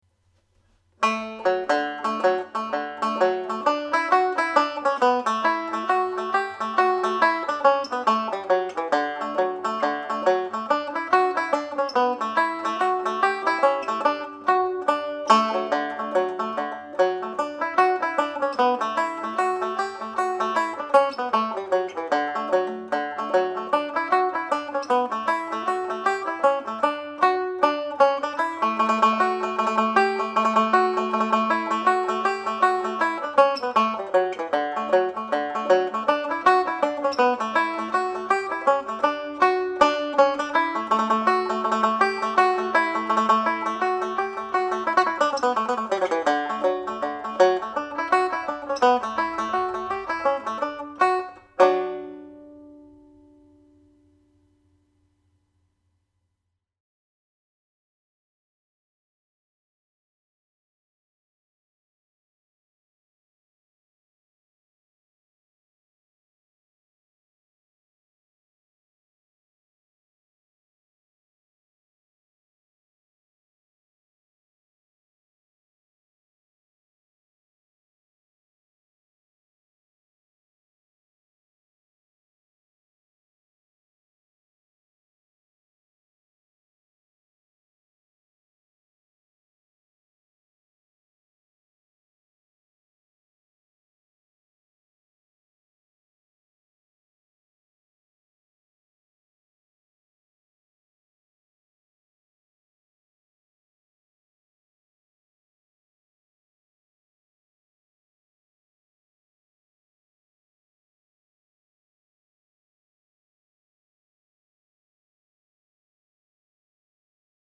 Hornpipe (D Major)
The-Harvest-Home-Hornpipe_NS.mp3